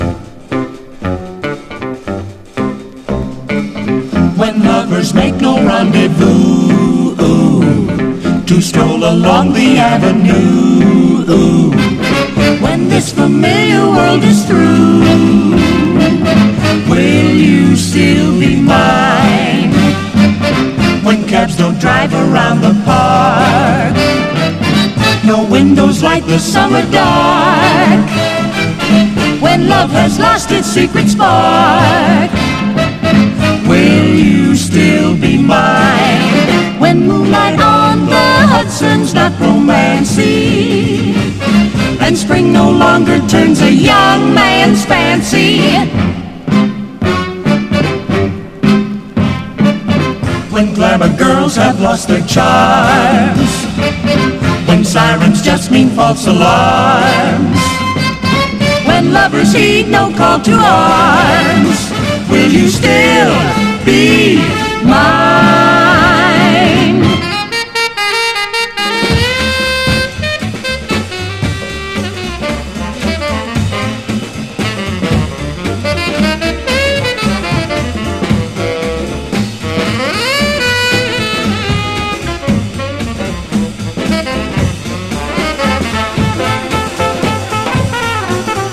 隙間グルーヴ→スウィングな